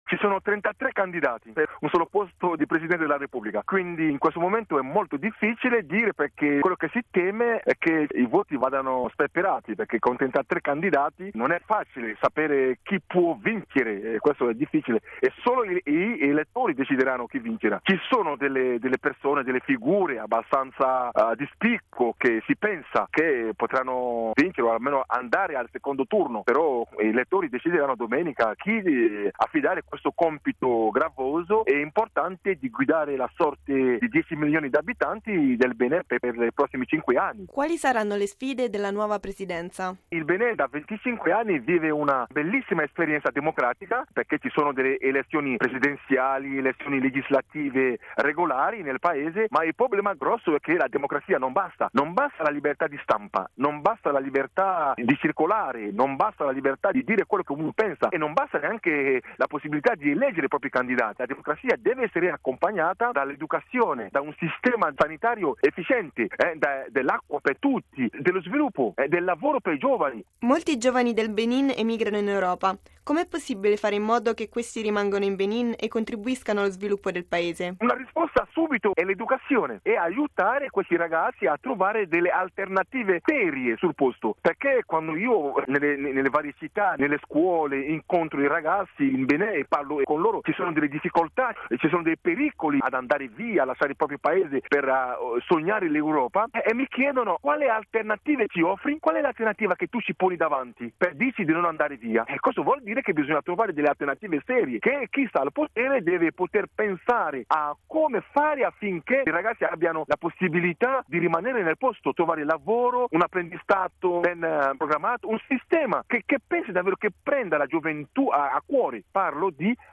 ha intervistato il giornalista beninese